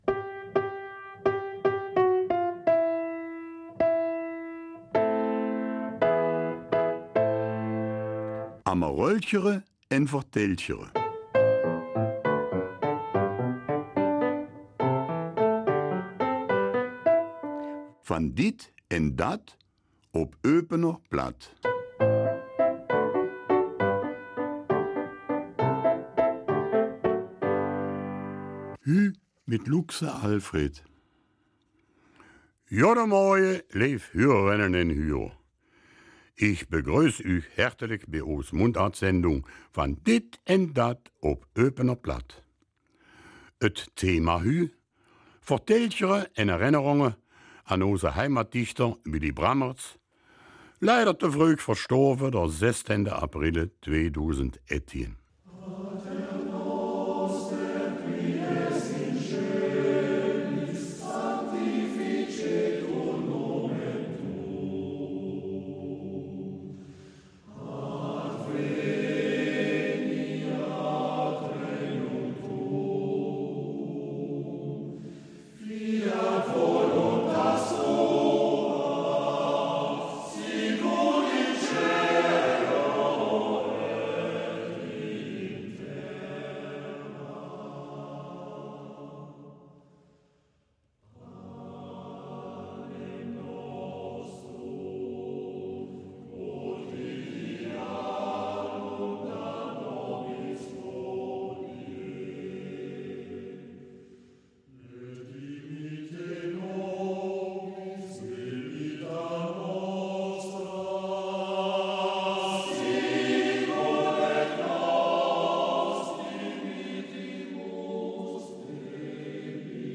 Eupener Mundart – 28. Juni